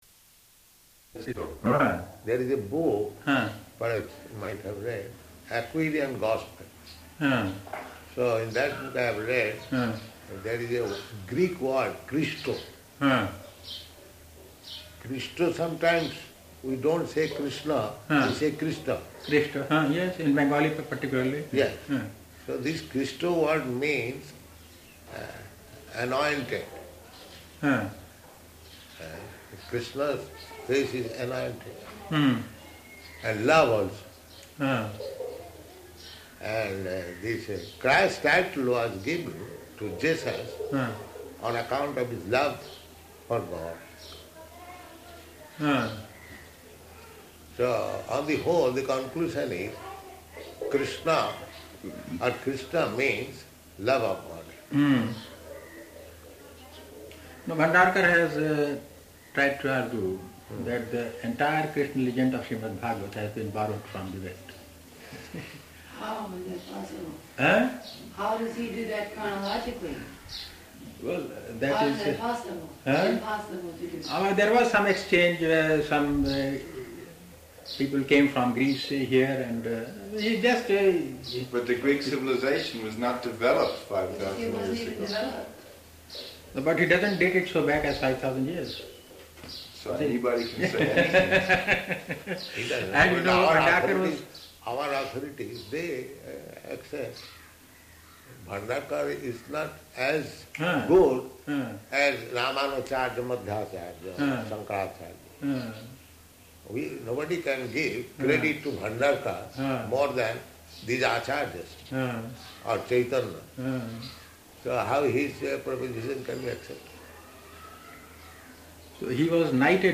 -- Type: Conversation Dated: March 12th 1972 Location: Vṛndāvana Audio file